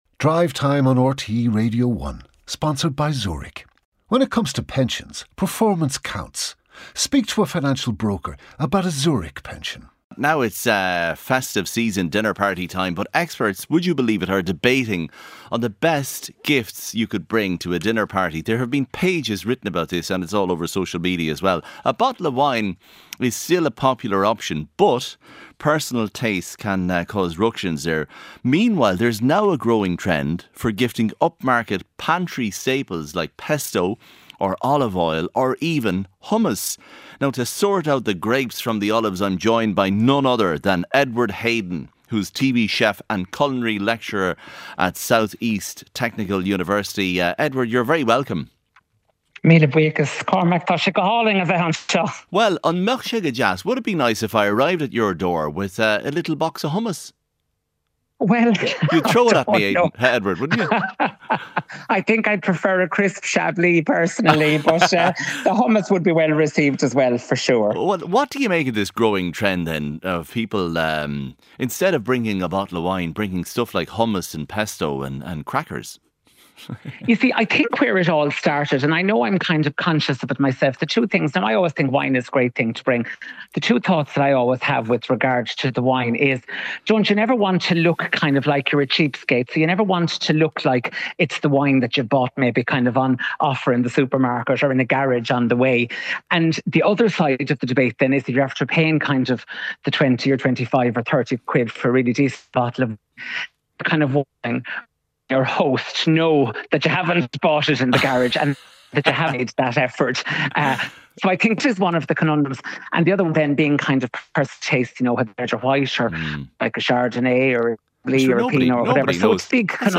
Highlights from the daily news programme with Sarah McInerney and Cormac Ó hEadhra. Featuring all the latest stories, interviews and special reports.